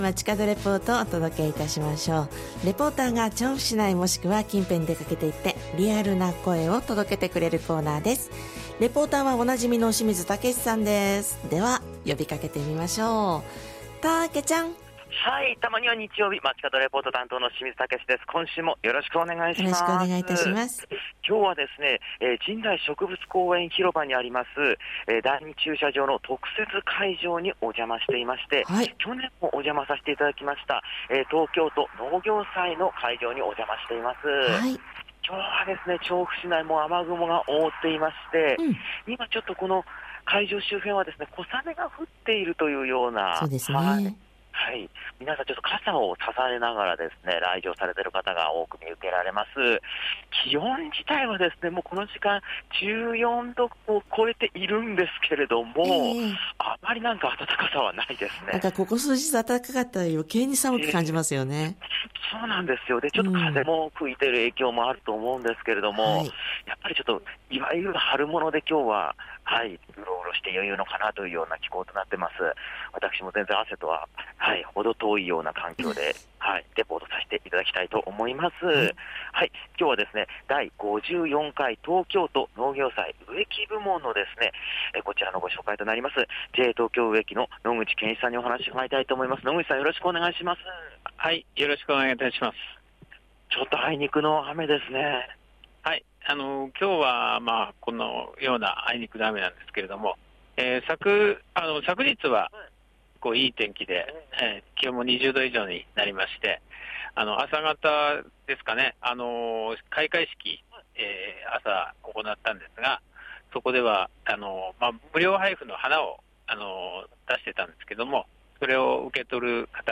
小雨が降り注ぐ中からお届けした本日の街角レポートは、 「 第54回 東京都農業祭 植木部門 」からレポートです！！